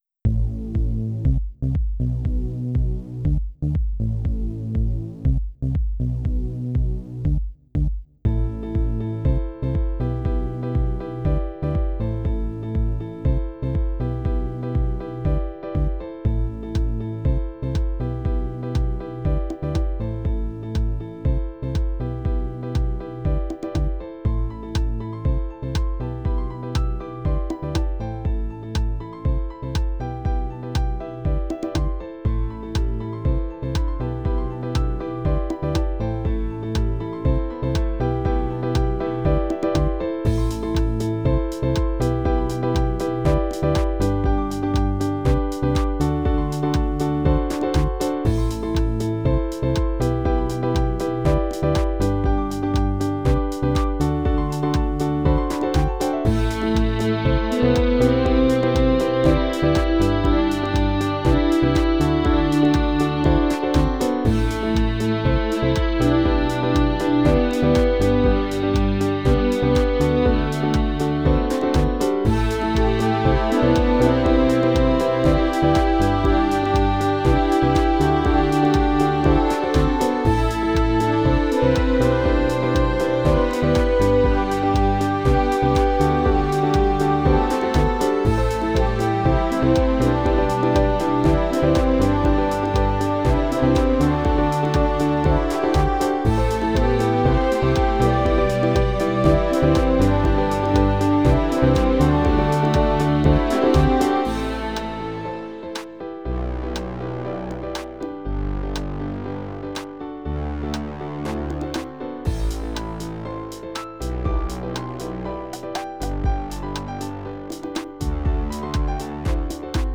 Tags: Piano, Percussion, Digital